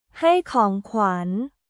ให้ของขวัญ　ハイ・コーン・クワン